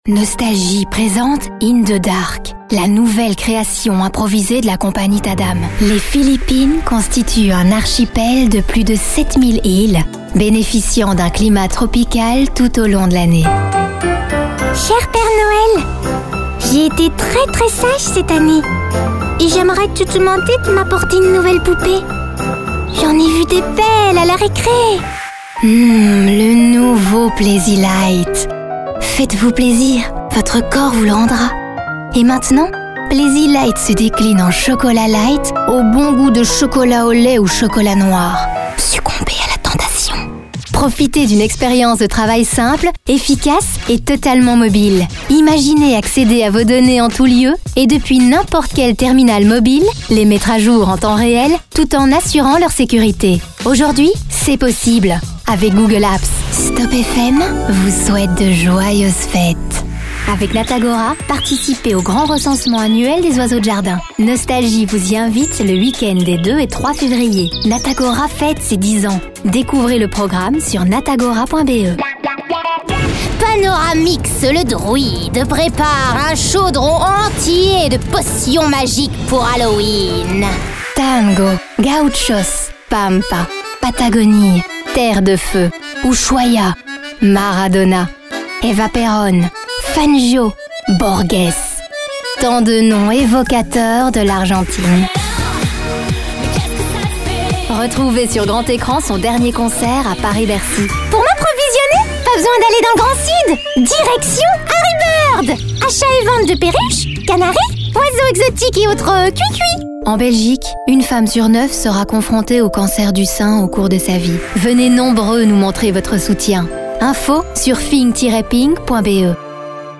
bande démo 2019
Voix Off